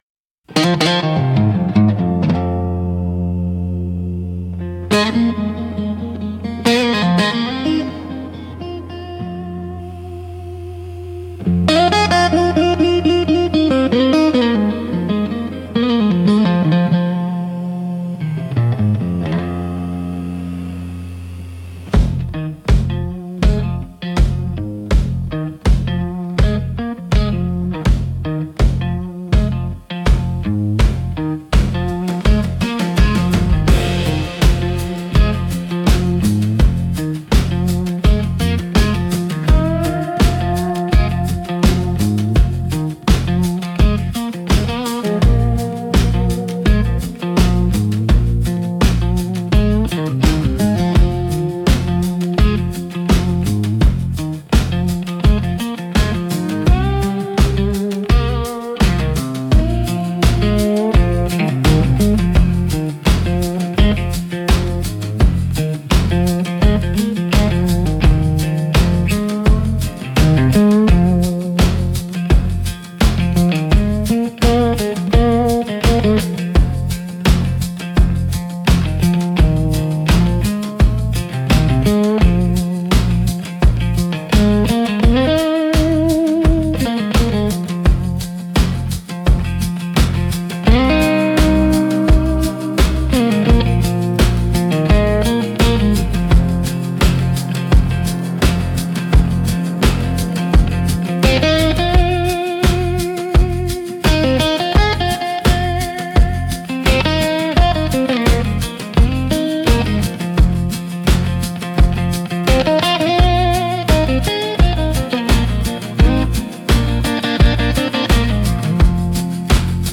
Instrumental - Backwater Echoes